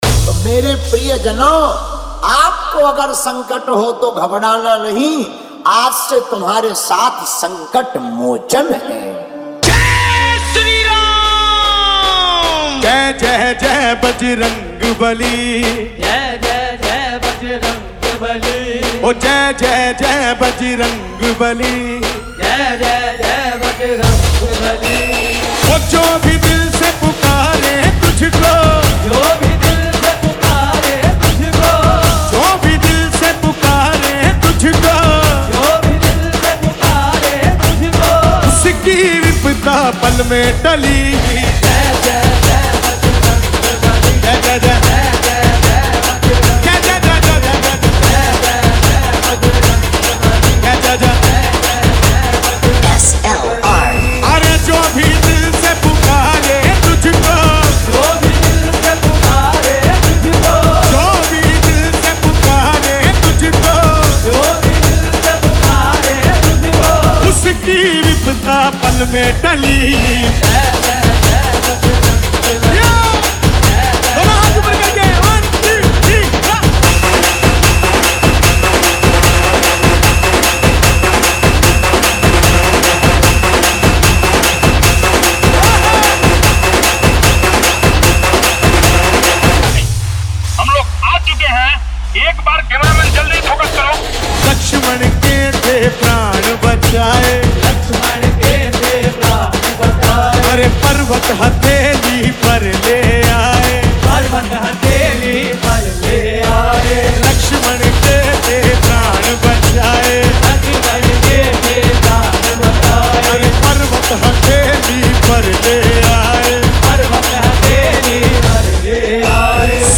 - bhakti dj song